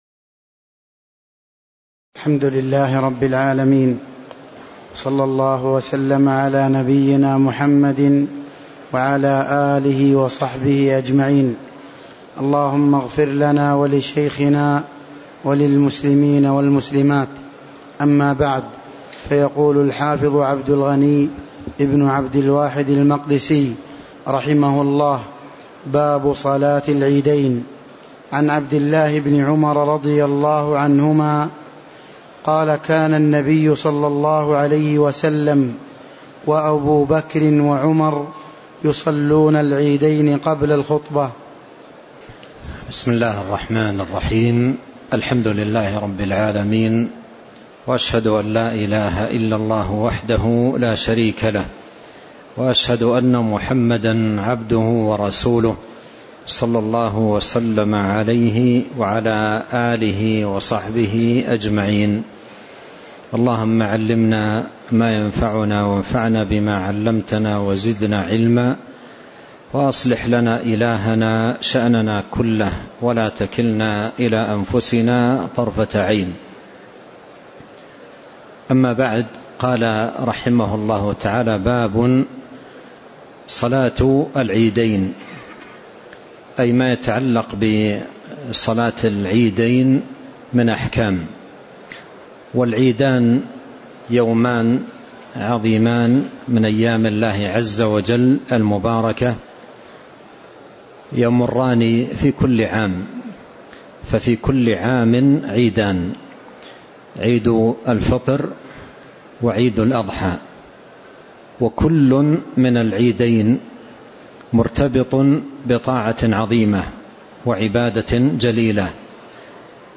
تاريخ النشر ١٨ جمادى الأولى ١٤٤٤ هـ المكان: المسجد النبوي الشيخ